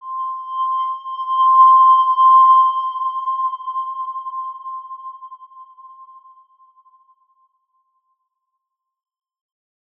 X_Windwistle-C5-mf.wav